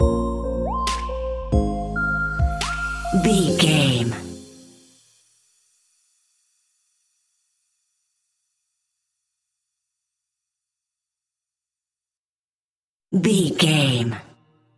Aeolian/Minor
Slow
hip hop
chilled
laid back
Deep
hip hop drums
hip hop synths
piano
hip hop pads